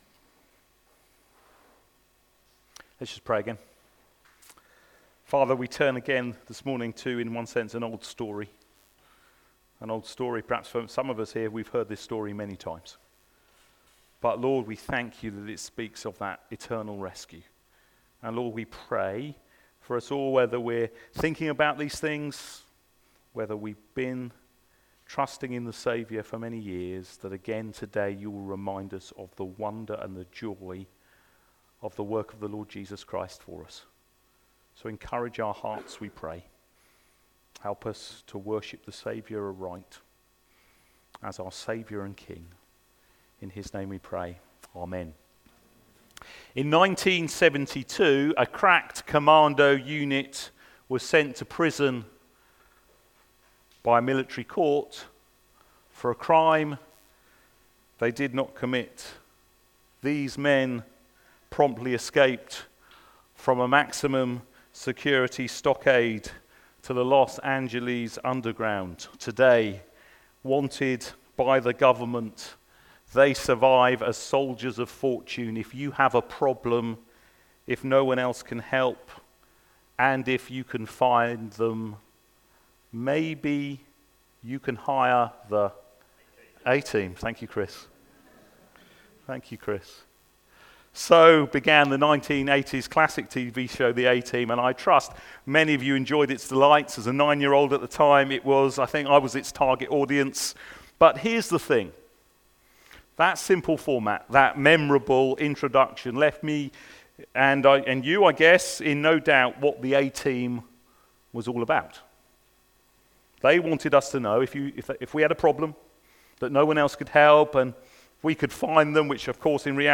LABC Sermons Mark 2v1-17 What Jesus wants you to know Play Episode Pause Episode Mute/Unmute Episode Rewind 10 Seconds 1x Fast Forward 30 seconds 00:00 / 35:47 Subscribe Share RSS Feed Share Link Embed